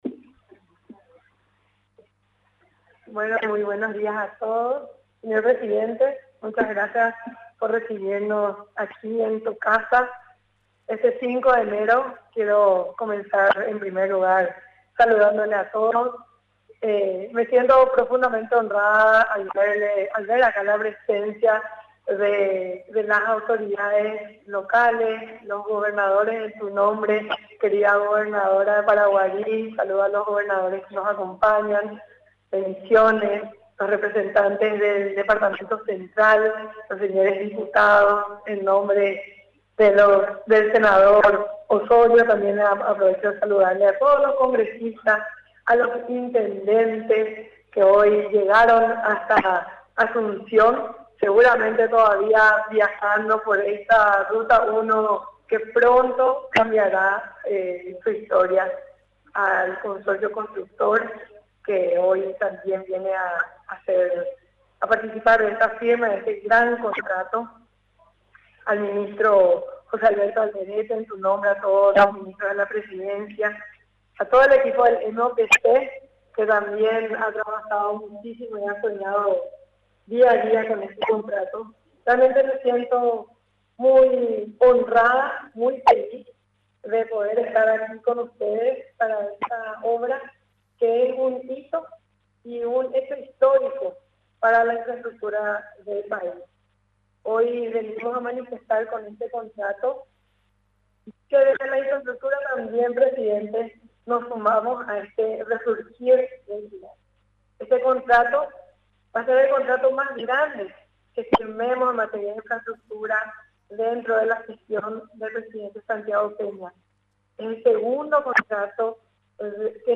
La implementación de la APP, es un logro de los paraguayos y de la clase política, destacó este lunes el presidente de la República, Santiago Peña, durante el acto de firma de contrato, realizado en el Salón Tirika de la Residencia Presidencial de Mburuvichá Róga.